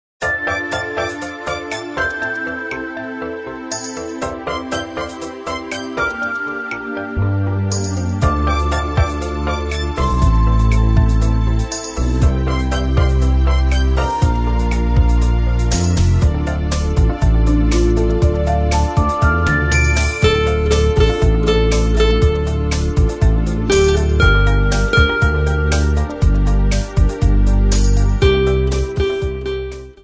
guitar melodies from Poland